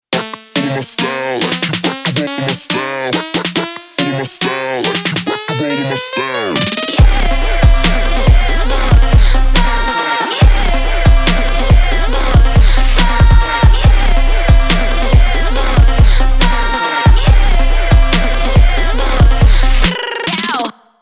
• Качество: 320, Stereo
Хип-хоп
Rap
корейские